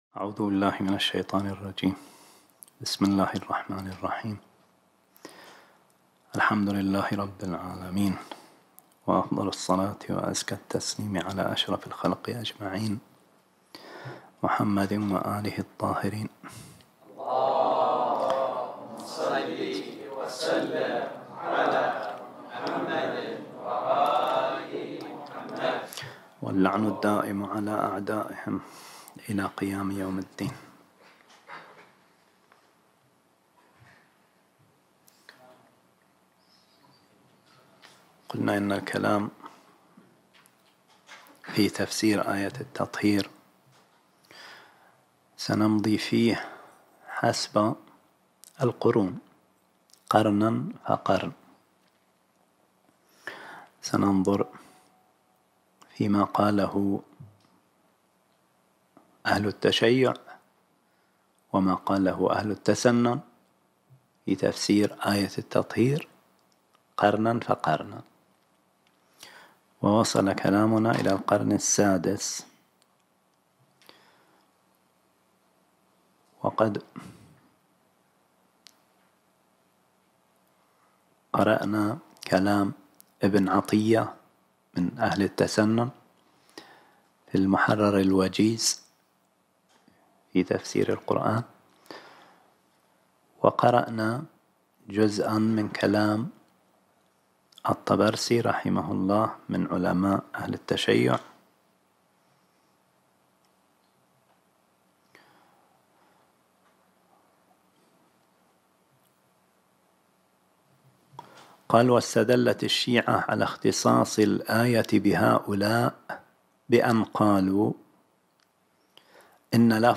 | الدرس (5) | تفسير آية التطهير (4) |